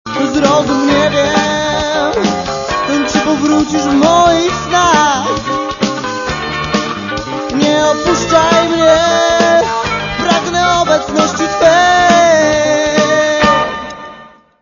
Trochę fankującego grania można usłyszeć w